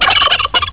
tacchino.au